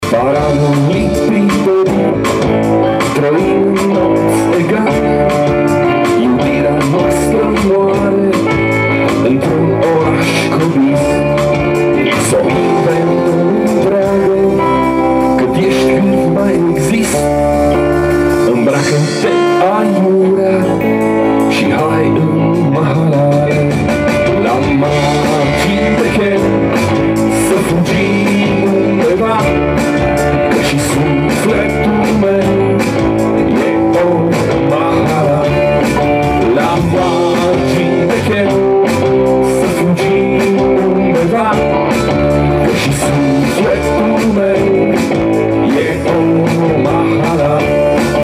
chitară bas
clape
voce și chitară.